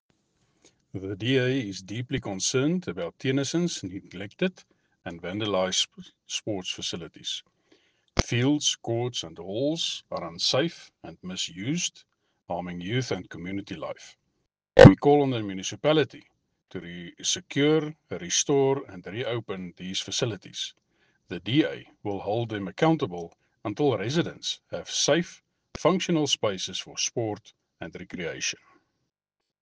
Afrikaans soundbites by Cllr Andre Kruger and